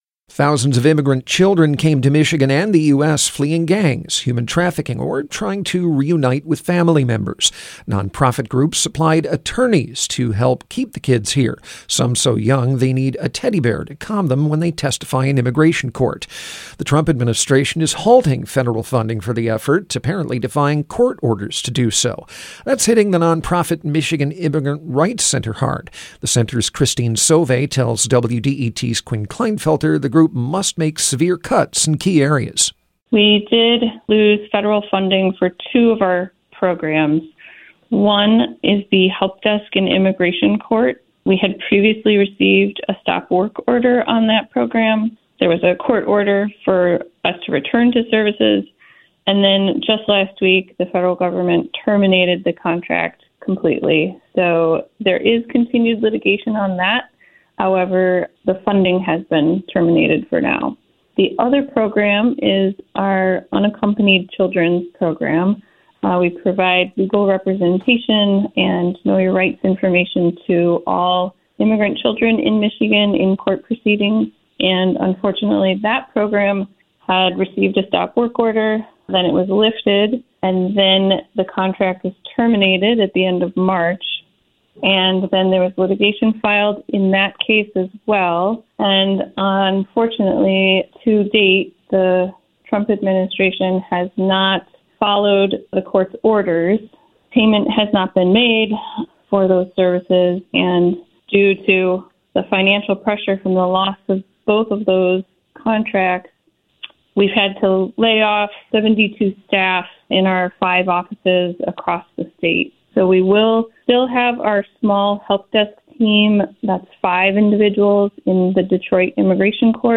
The following interview has been edited for clarity and length.